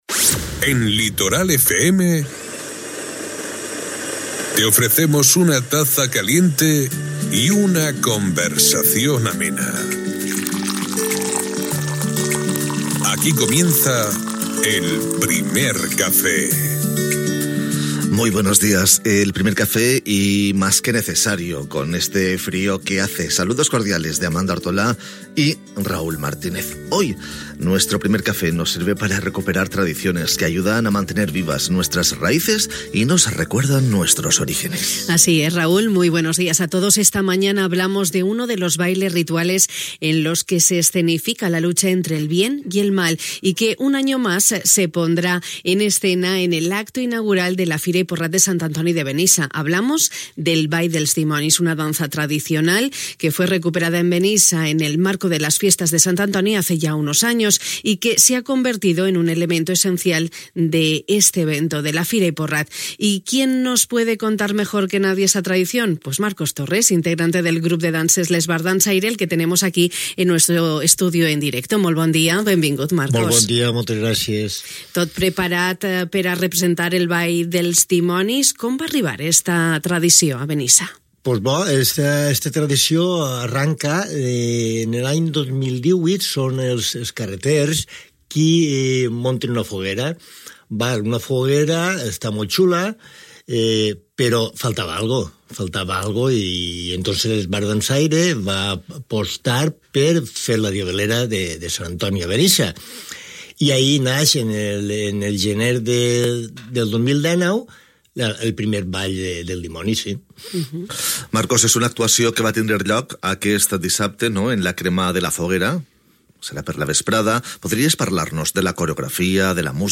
Hui al Primer Café de Radio Litoral hem continuat recuperant tradicions que ajuden a mantenir vives les nostres arrels i ens recorden els nostres orígens. Aquest matí hem parlat d’un dels balls rituals on s’escenifica la lluita entre el bé i el mal, el Ball dels Dimonis, i que un any més es posarà en escena a l’acte inaugural de la Fira i el Porrat de Sant Antoni de Benissa.